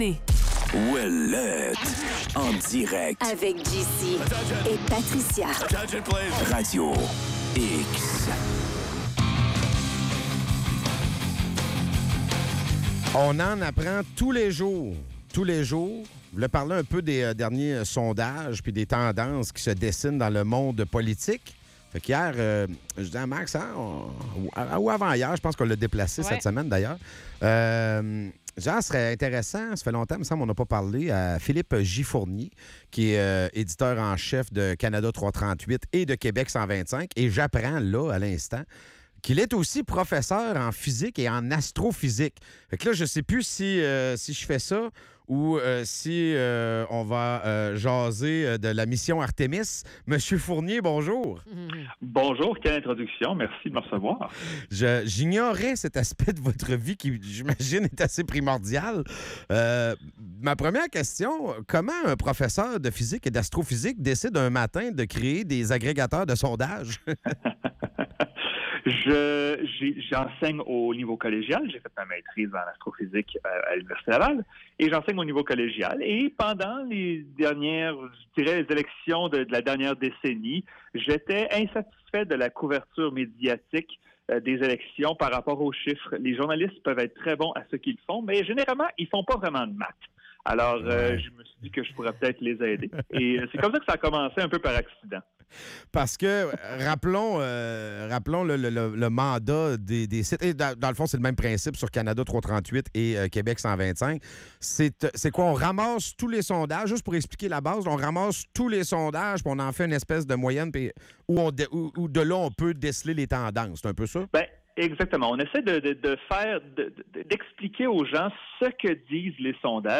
En entrevue